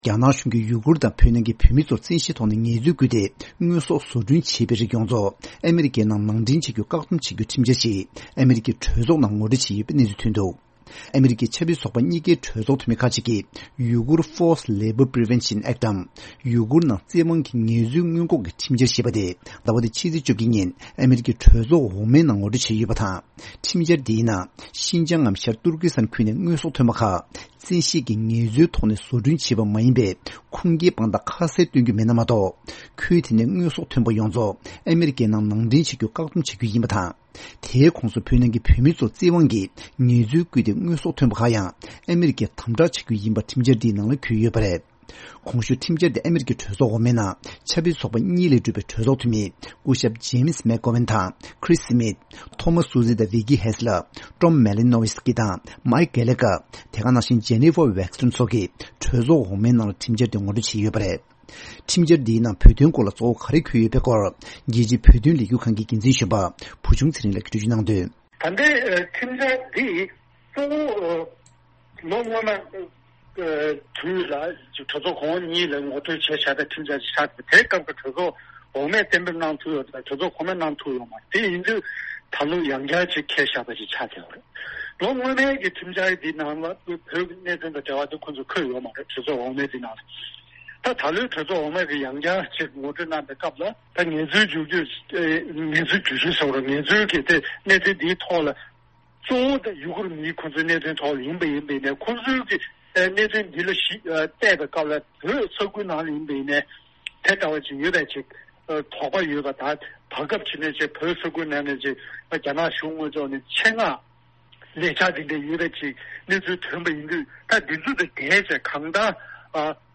གནས་ཚུལ་སྙན་སྒྲོན་ཞུ་རྒྱུ་རེད།།